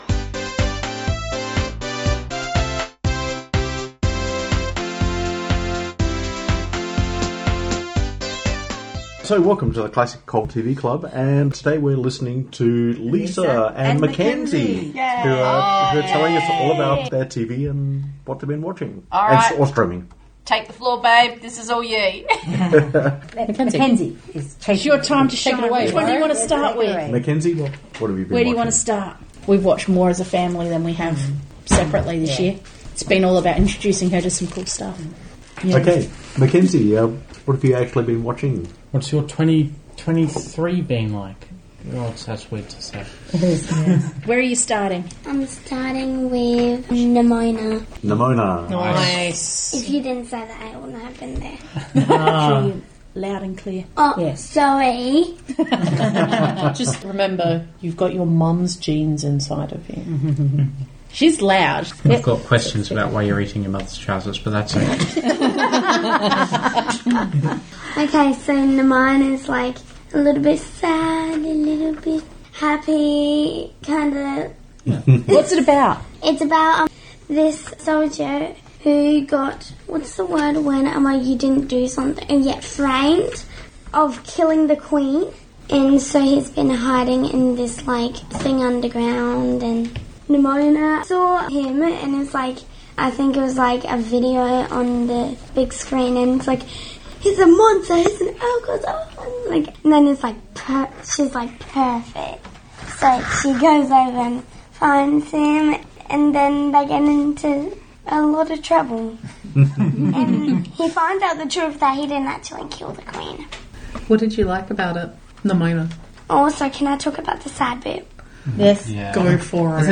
Podcast recorded14-o1-2024 in Gawler South